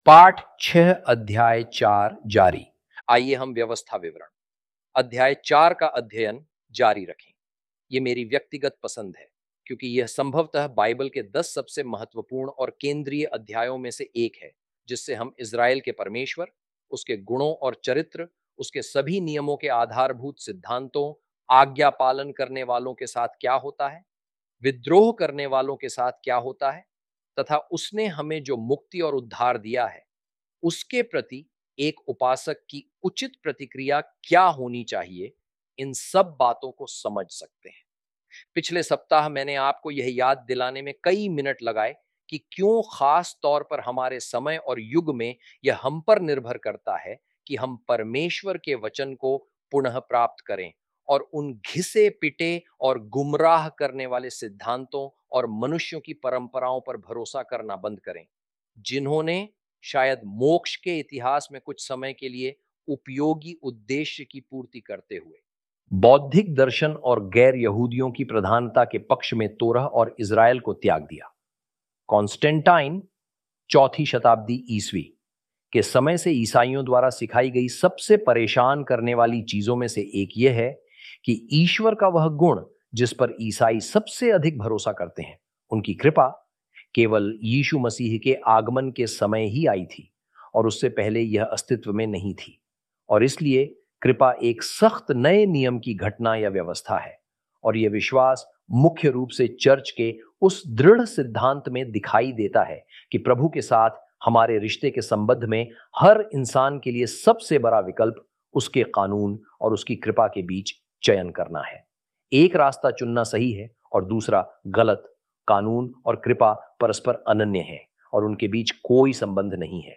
hi-audio-deuteronomy-lesson-6-ch4.mp3